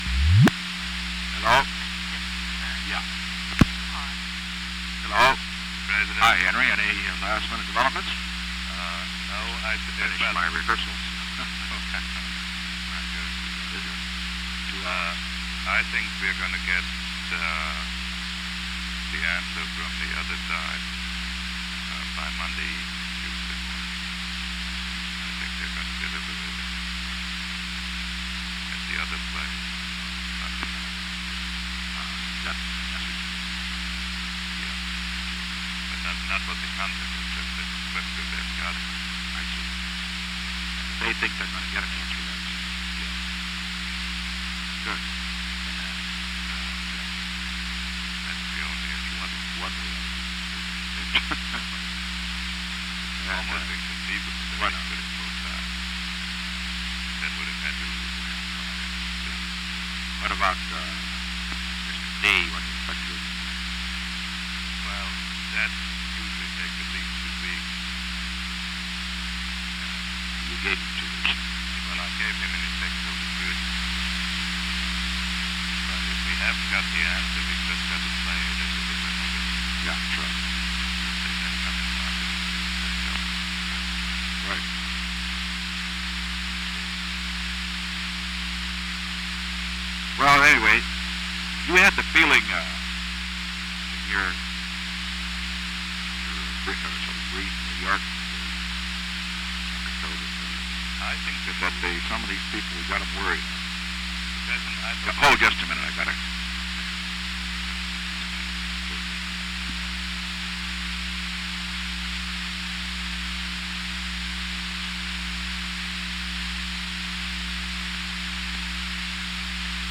Secret White House Tapes
Conversation No. 5-12
Location: White House Telephone
The President talked with Henry A. Kissinger.